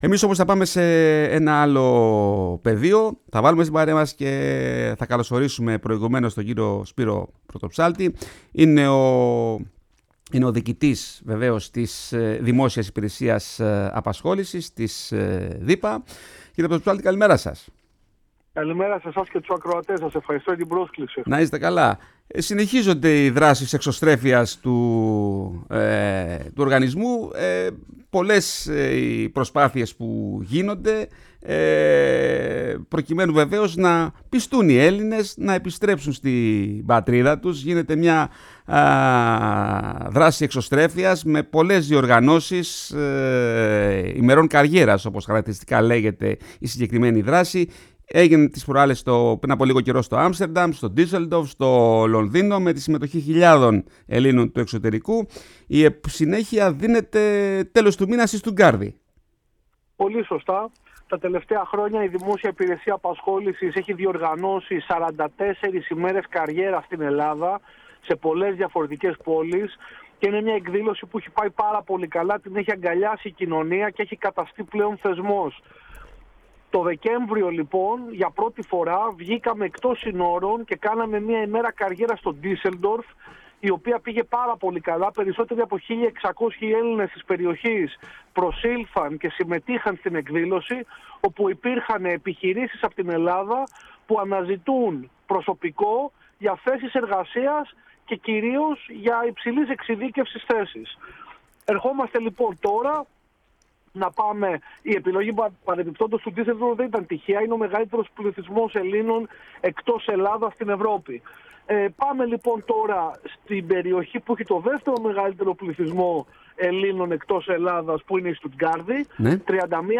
Ο Διοικητής της ΔΥΠΑ Σπύρος Πρωτοψάλτης στην εκπομπή “Η Ελλάδα στον κόσμο” | 21.05.2025